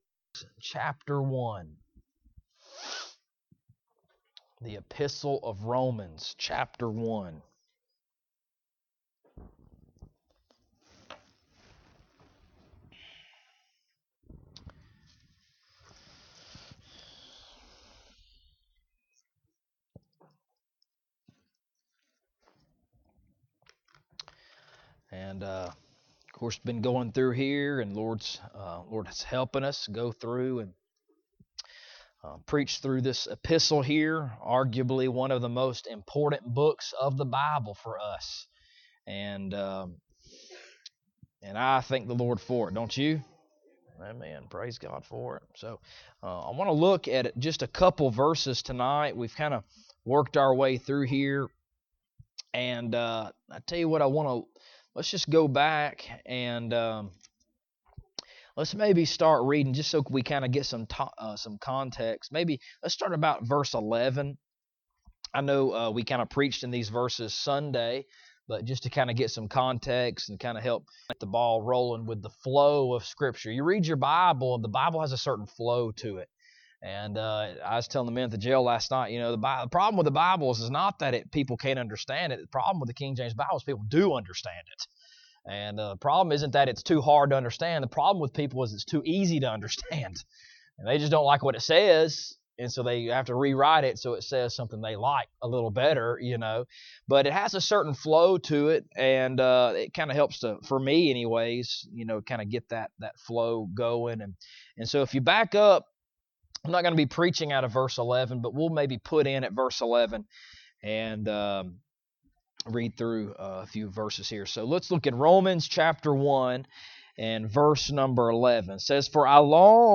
Romans Passage: Romans 1:11-17 Service Type: Wednesday Evening Topics